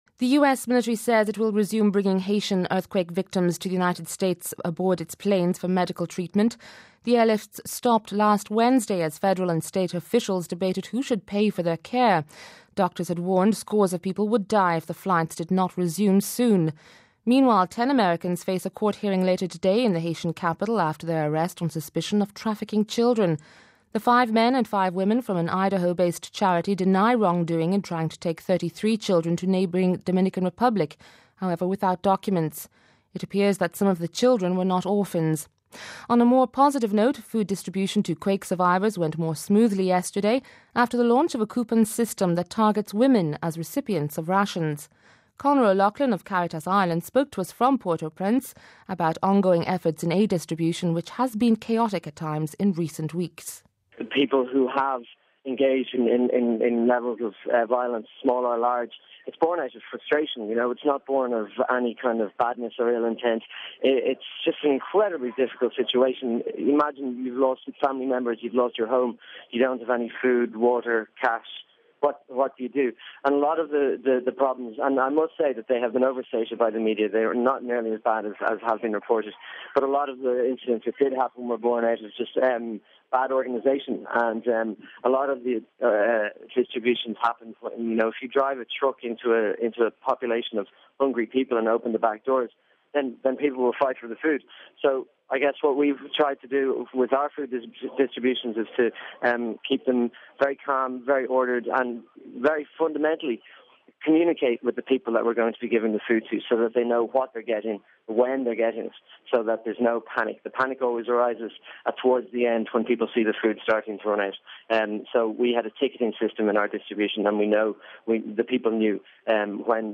spoke to us from Port-Au-prince about ongoing efforts in aid distribution which has been chaotic at times in recent weeks